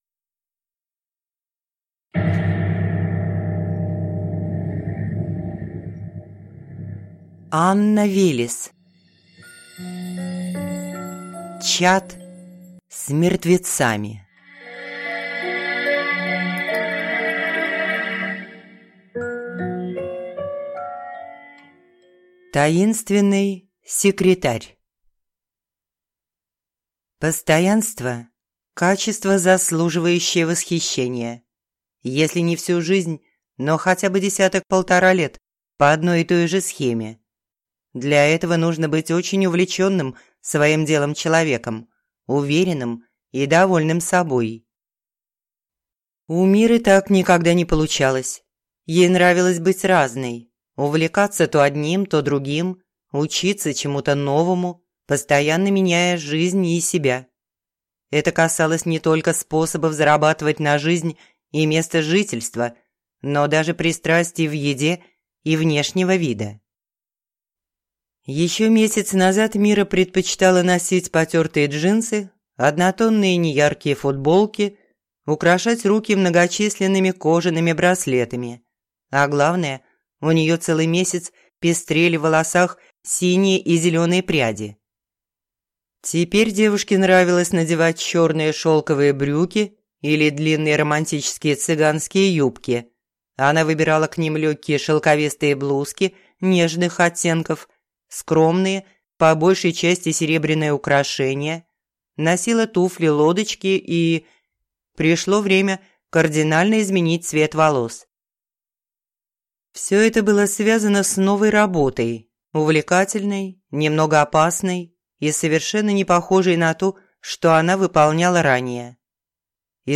Аудиокнига Чат с мертвецами | Библиотека аудиокниг